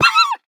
Minecraft Version Minecraft Version snapshot Latest Release | Latest Snapshot snapshot / assets / minecraft / sounds / mob / fox / death1.ogg Compare With Compare With Latest Release | Latest Snapshot